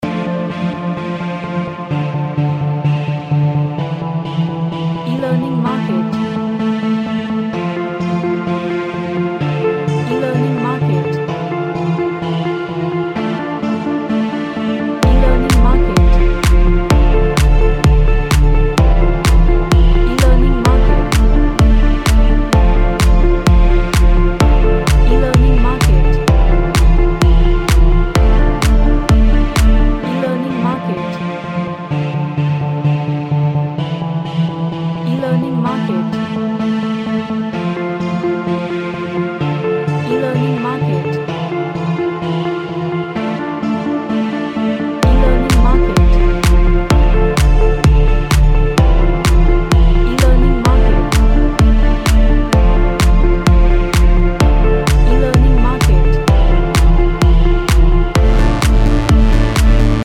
An EDM track with lots of energy and synth melody.
Energetic